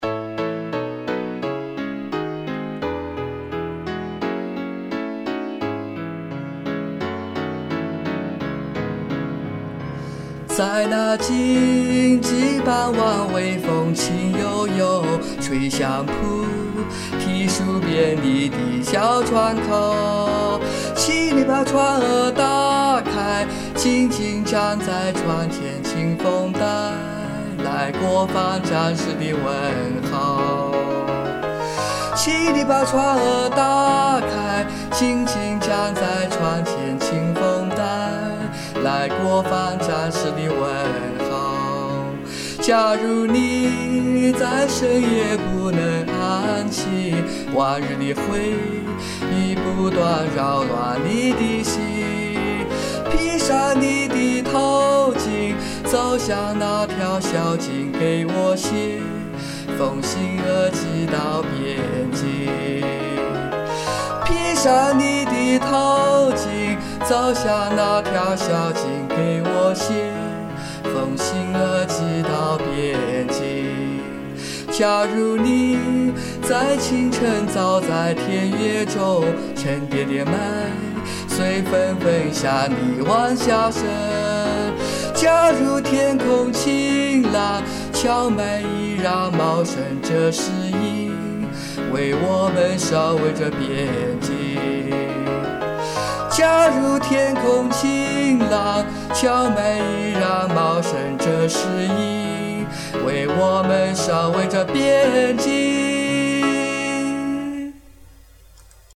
刚好我有这个歌的原版钢琴伴奏谱，就随手给你乱弹了一下。我的谱子是俄文的，刚好朋友会唱中文的，就随便录了一下。